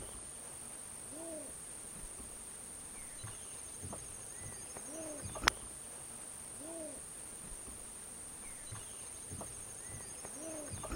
Mirasol Chico (Botaurus exilis)
Nombre en inglés: Least Bittern
Localidad o área protegida: Colonia Carlos Pellegrini
Condición: Silvestre
Certeza: Vocalización Grabada